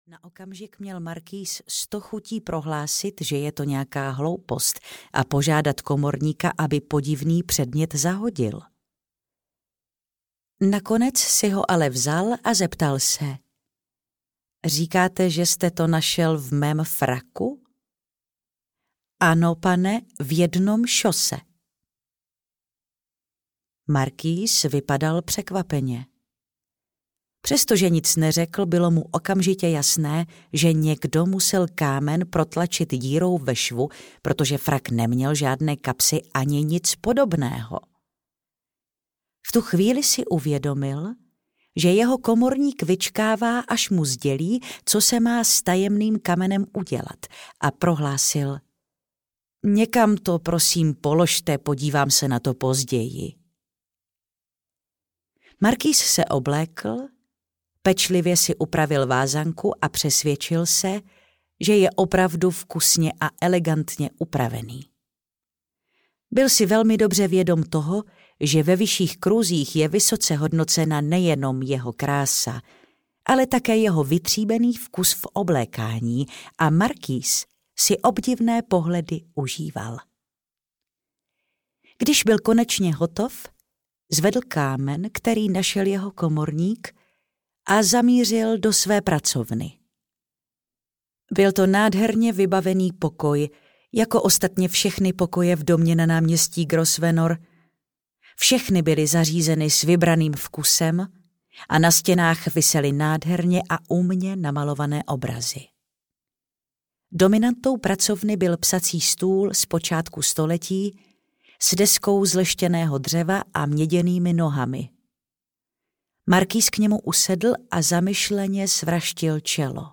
Čarodějka audiokniha
Ukázka z knihy